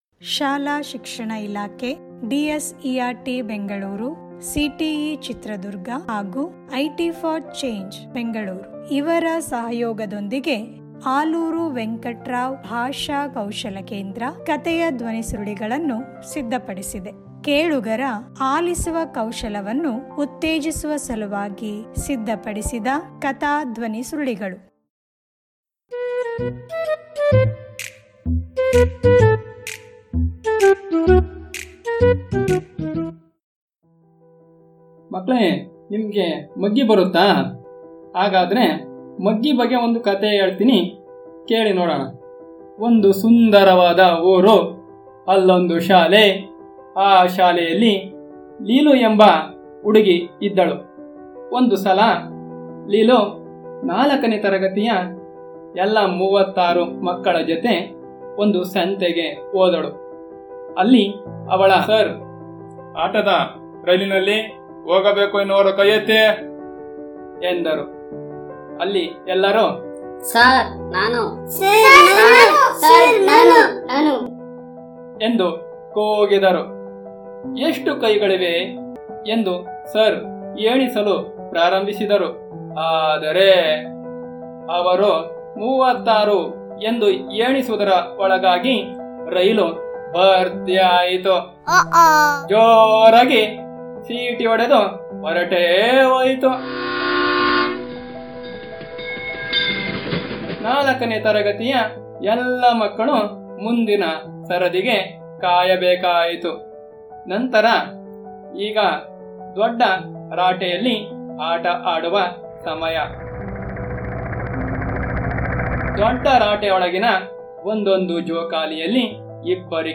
ಧ್ವನಿ ಕಥೆ ಲಿಂಕ್: